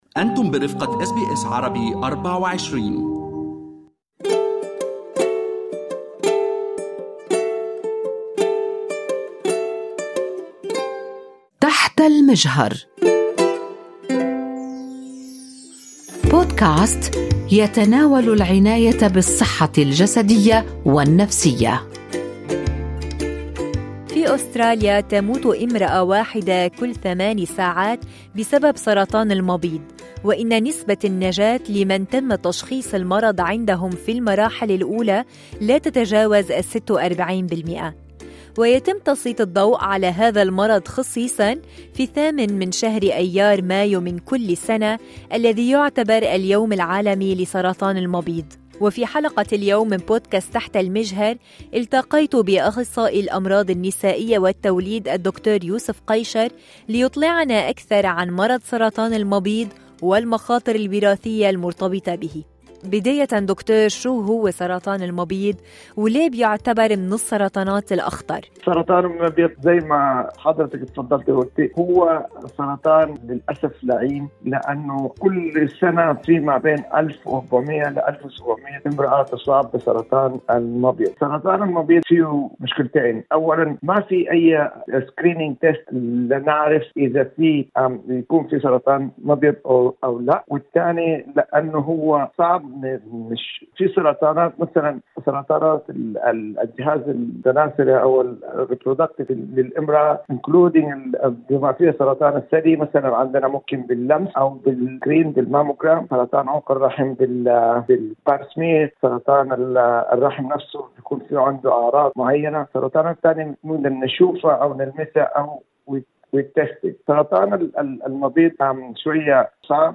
في حديث مع أخصائي الأمراض النسائية والتوليد